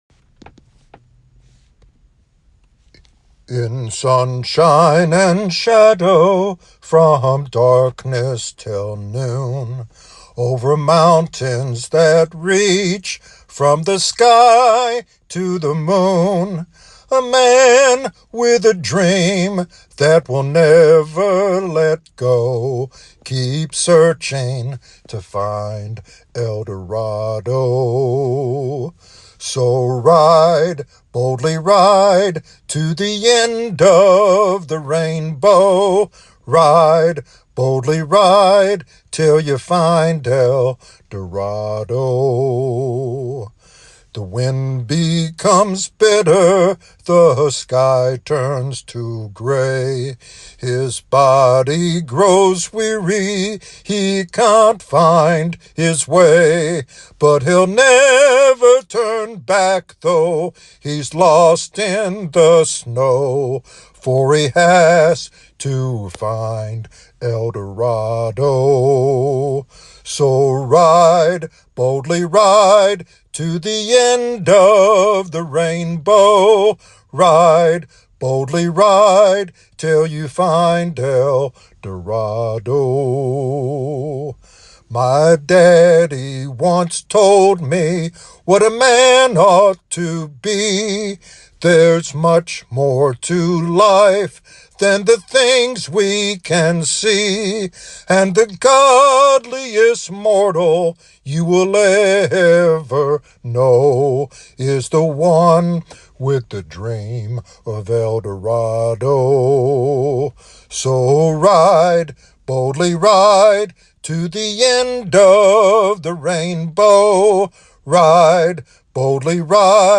This devotional sermon encourages believers to trust in God's promises and boldly continue their pursuit of divine purpose.